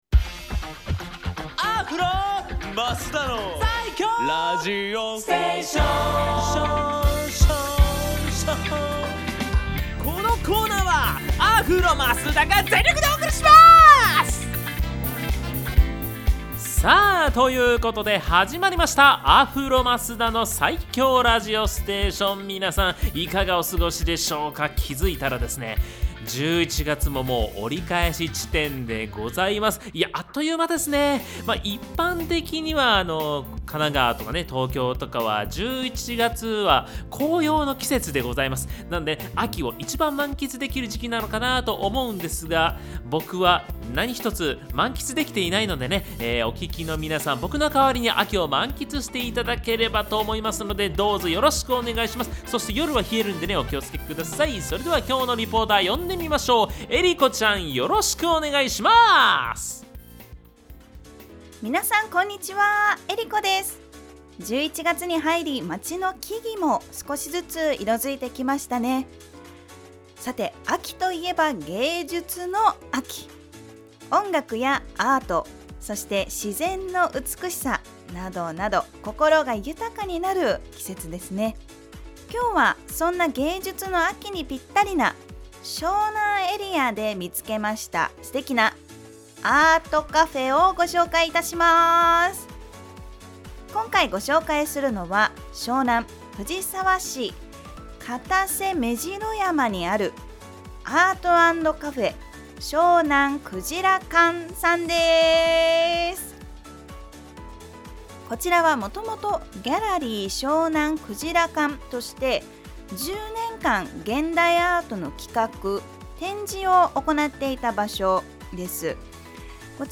こちらのブログでは、FM83.1Mhzレディオ湘南にて放送されたラジオ番組「湘南MUSICTOWN Z」内の湘南ミュージックシーンを活性化させる新コーナー！